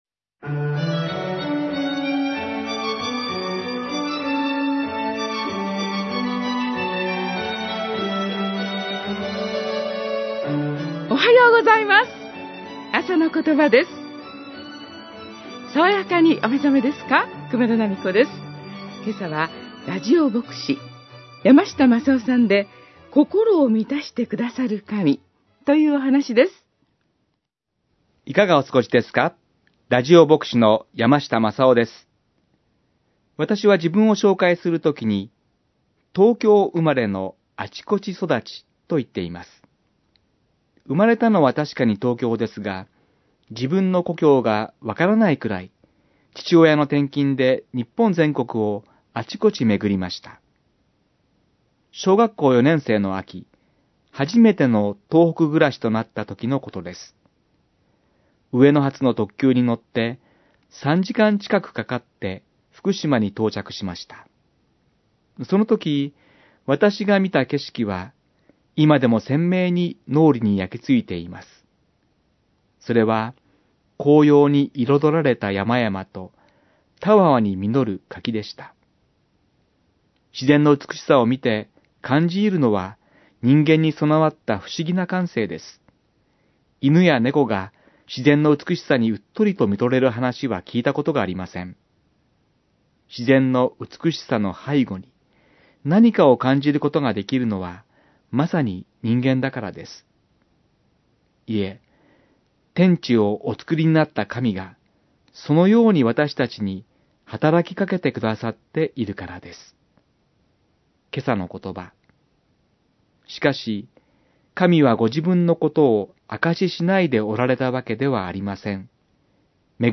メッセージ： 心を満たしてくださる神